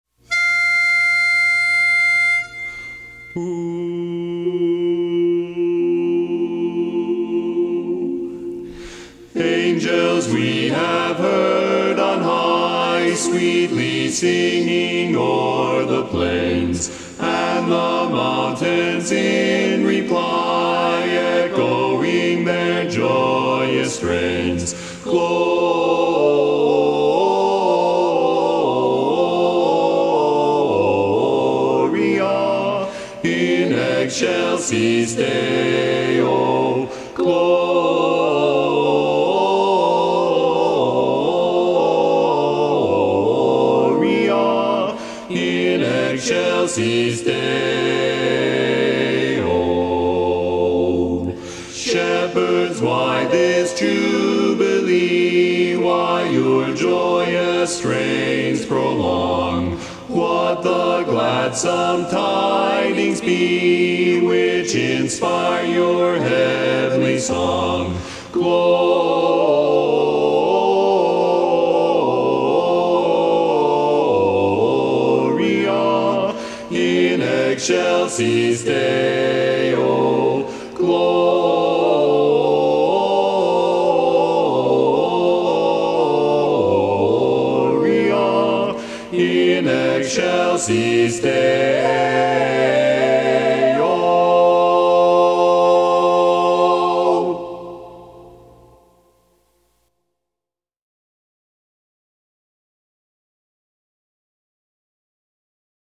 Barbershop
Bass